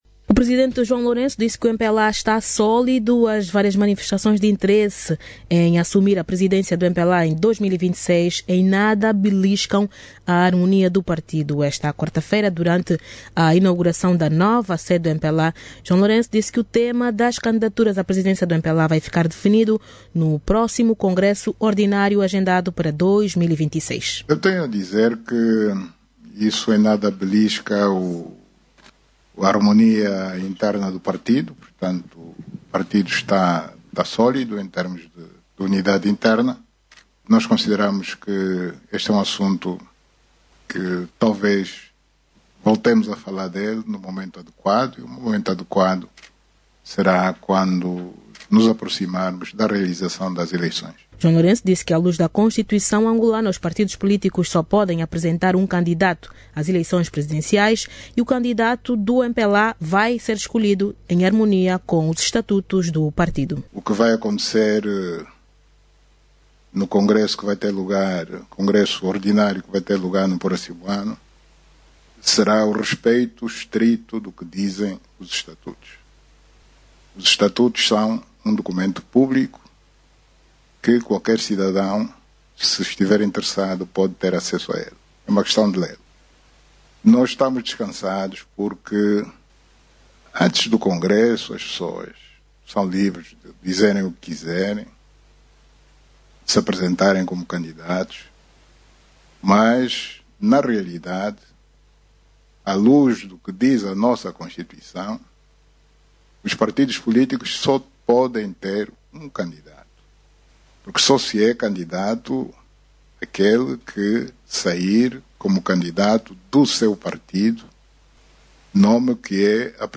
O Presidente do MPLA esclareceu ontem, quarta-feira(10), que o candidato do partido dos camaradas às eleições presidenciais de 2027, vai ser conhecido apenas no Congresso do próximo ano. João Lourenço que falava em conferência de imprensa depois de orientar a cerimónia de inauguração da nova sede nacional do MPLA, sublinhou que o partido no poder em Angola, tem a possibilidade de escolher um candidato à luz dos estatutos.